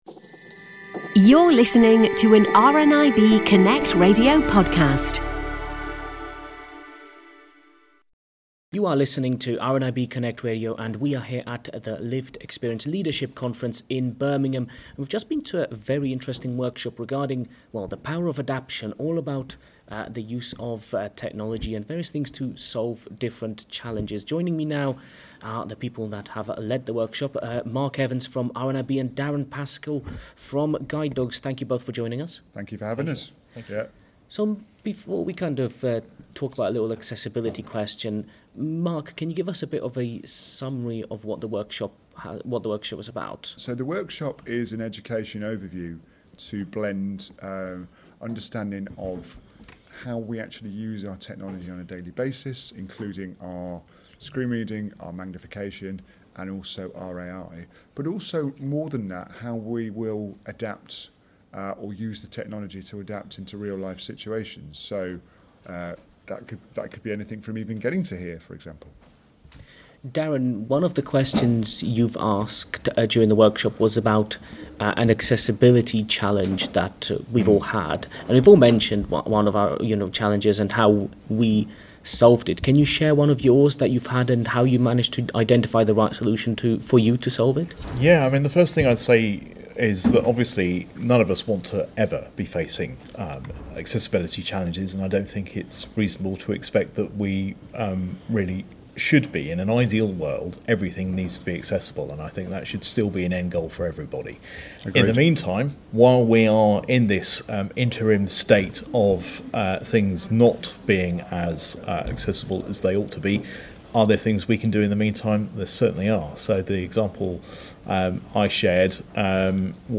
He spoke to the organisers of the workshop afterwards.